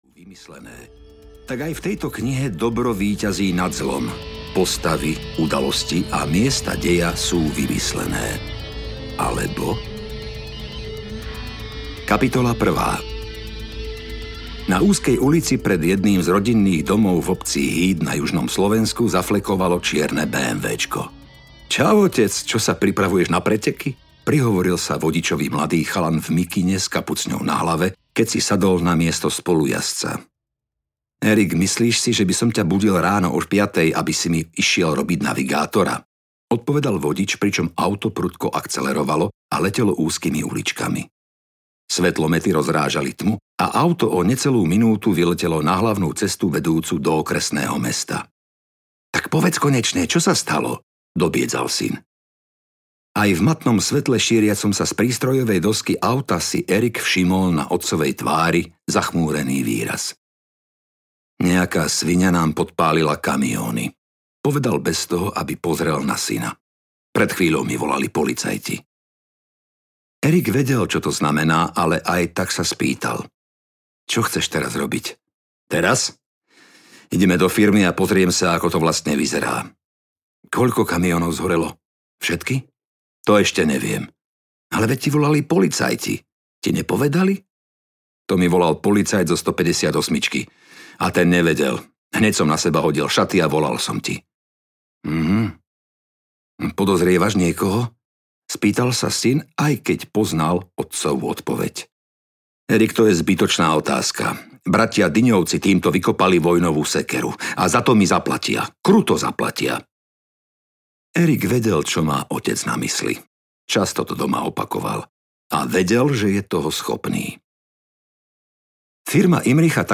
Mafiánska poprava? audiokniha
Ukázka z knihy